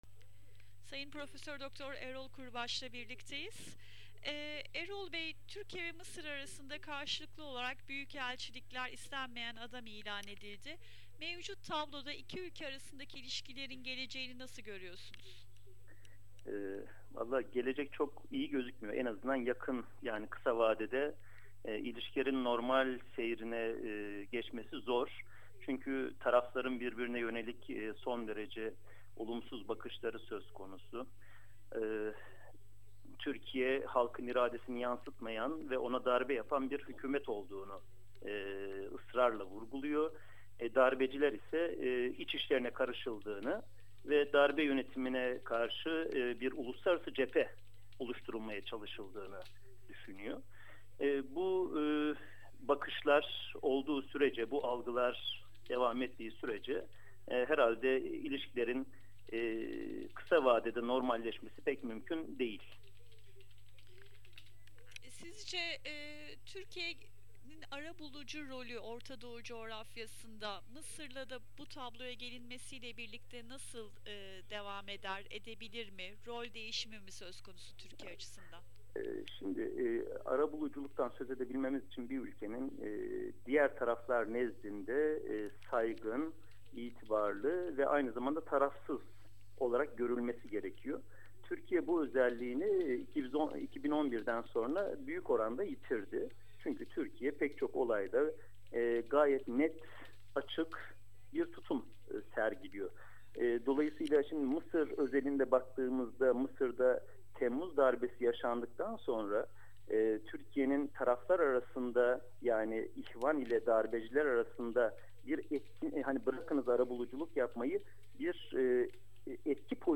Söyleşi